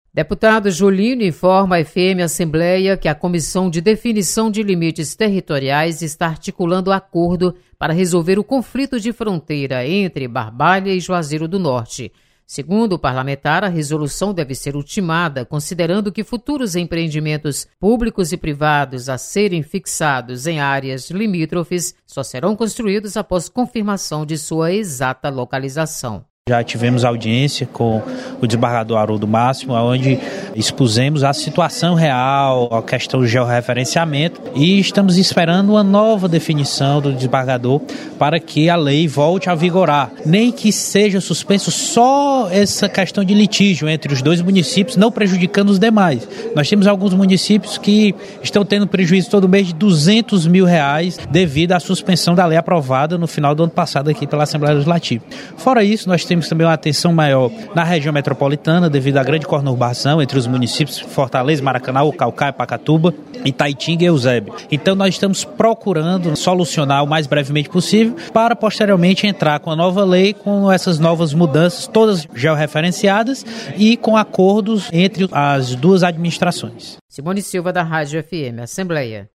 Deputado Julinho intermedeia conflito territorial entre Barbalha e Juazeiro do Norte. Repórter